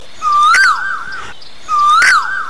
asian-koel.mp3